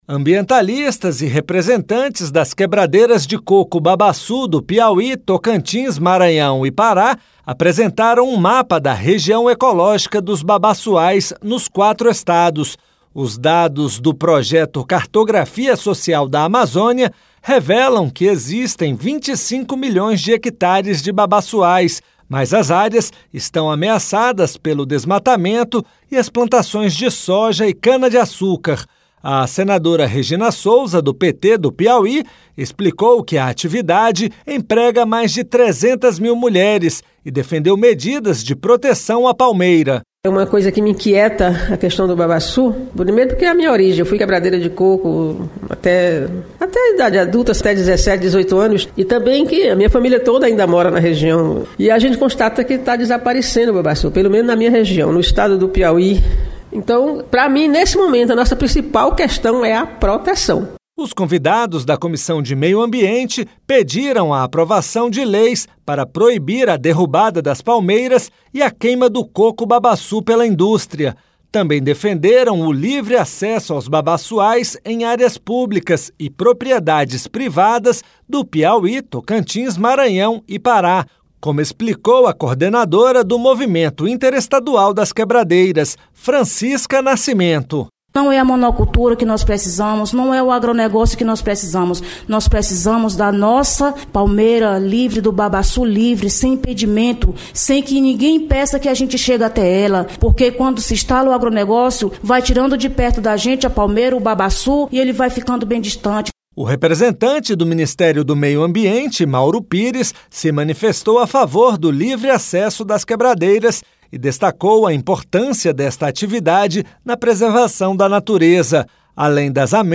O Congresso Nacional precisa aprovar uma lei que assegure às quebradeiras de coco o livre acesso aos babaçuais em áreas públicas e fazendas do Piauí, Tocantins, Maranhão e Pará. A reivindicação foi feita nesta quinta-feira (1º) em audiência pública na Comissão de Meio Ambiente, Defesa do Consumidor, Fiscalização e Controle (CMA), que discutiu medidas de proteção da região ecológica dos babaçuais.